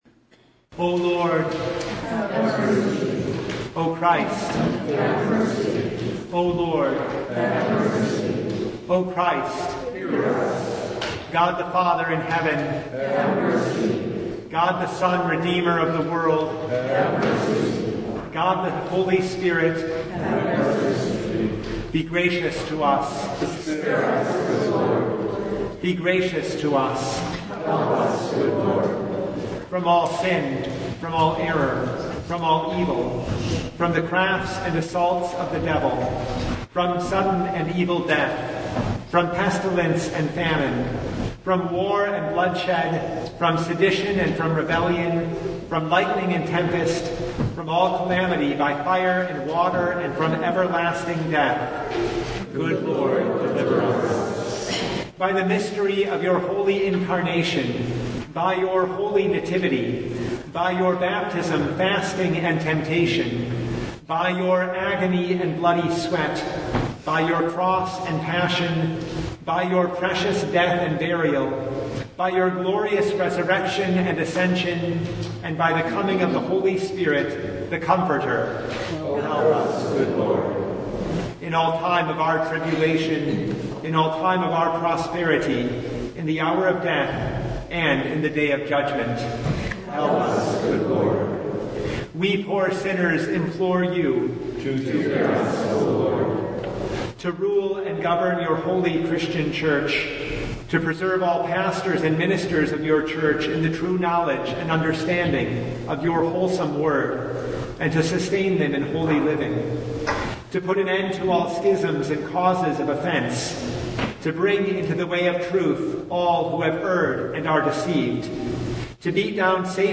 Service Type: Lent Midweek Noon
Full Service